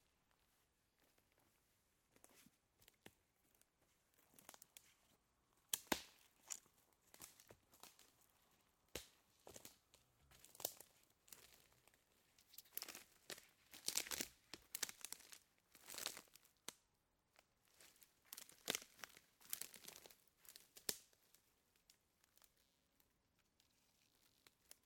Walking on branches
Duration - 24 s Environment - Open air, breathing, birds, and flies, body movements rubbing of clothes. Description - Walking, breaking snapping branches twigs, footsteps, motion, wind, breathing, uneven steps.